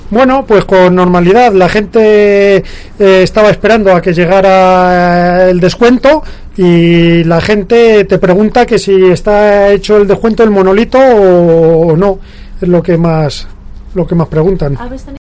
Empleado estación de servicio. Normalidad en el primer día de descuentos